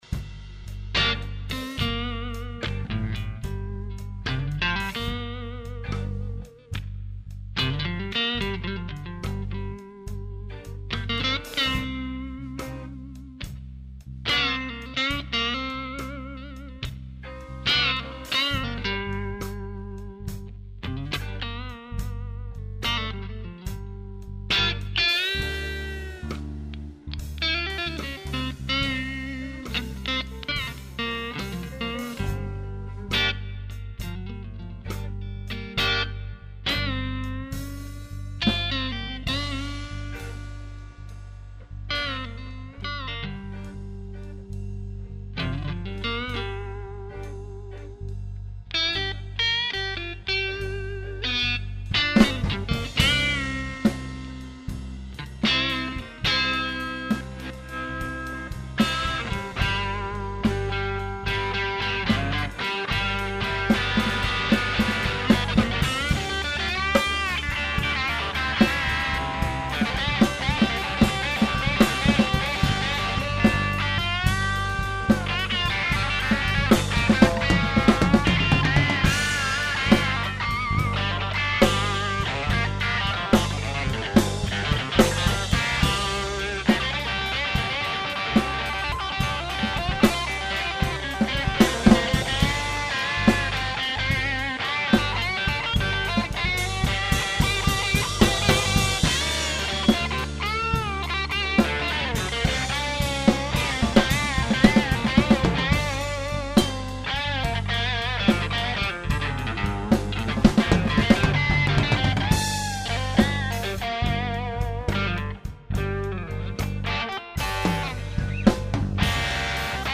-Preamp a lampe 2x 12ax7, midi
-son chaud et precis
BluesMix2.mp3